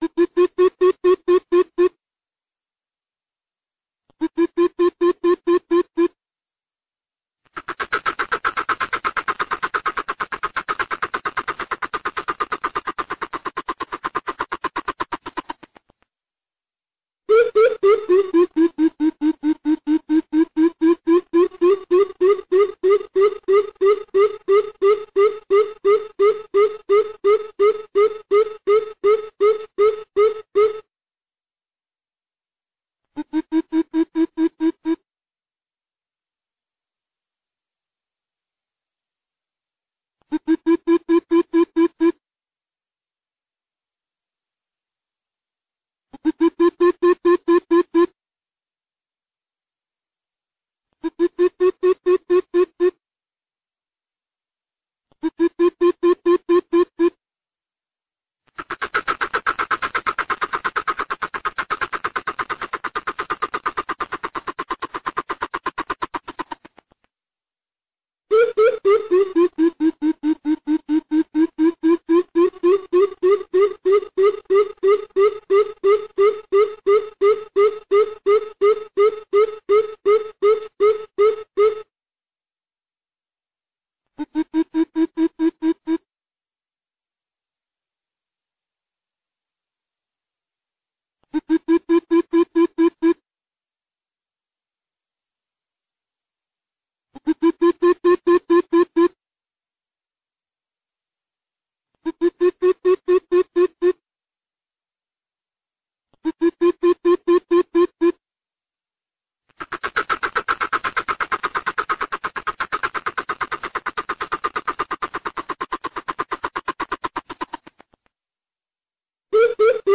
Tiếng Bìm Bịp kêu
Donwload tiếng Bìm Bịp kêu mp3, tải file tiếng chim bìm bịp kêu hay nhất, chuẩn nhất, không có tạp âm.
Với nhịp điệu "bịp... bịp..." trầm thấp, vang vọng và có độ nặng đặc biệt, tiếng kêu này thường xuất hiện vào lúc thủy triều lên hoặc trong những không gian tĩnh mịch ven sông.
• Âm hưởng trầm ấm và huyền bí: Tiếng Bìm Bịp có tần số thấp, tạo cảm giác sâu lắng và hơi có chút bí ẩn.
• Chất lượng bản thu chuyên nghiệp: File âm thanh đã được xử lý lọc nhiễu tần số cao, giữ lại dải âm trầm đặc trưng của loài chim này, đảm bảo độ sắc nét khi phát trên các hệ thống loa có loa bass.